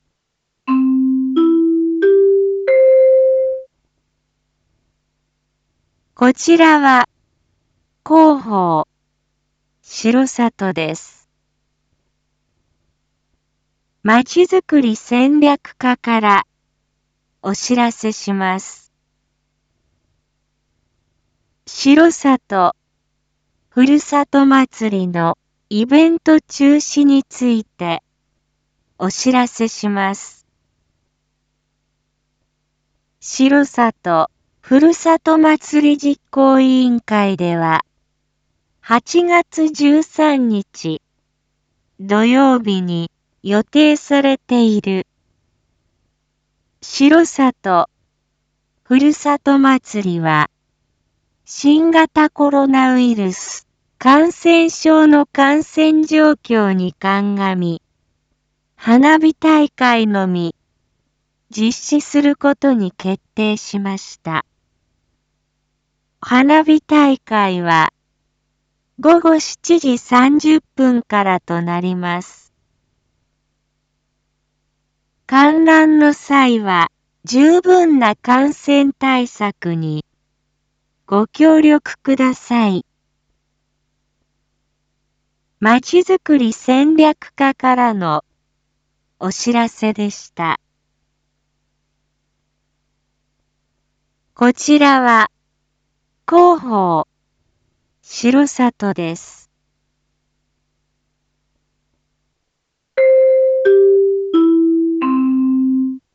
一般放送情報
Back Home 一般放送情報 音声放送 再生 一般放送情報 登録日時：2022-08-11 19:01:47 タイトル：R4.8.11 19時放送分 インフォメーション：こちらは広報しろさとです。